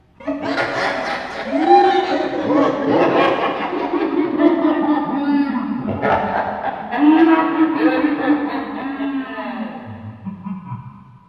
Category: Scary Ringtones